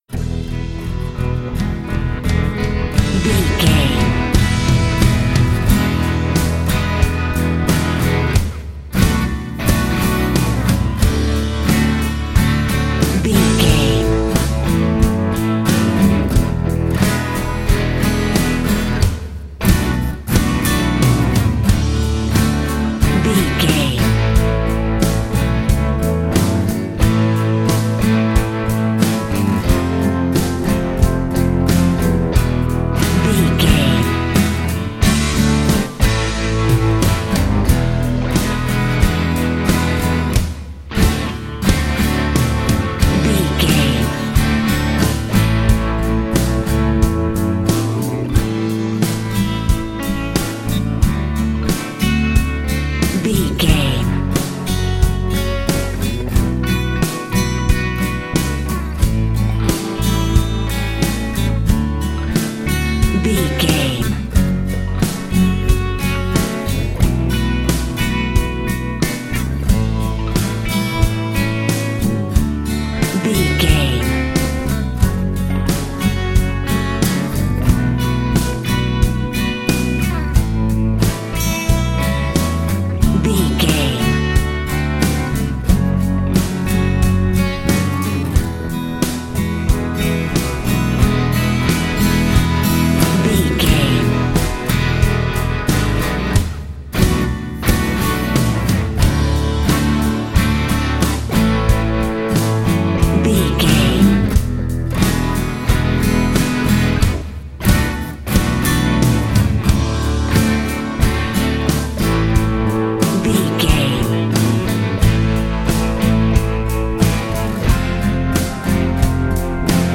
Ionian/Major
drums
electric guitar
bass guitar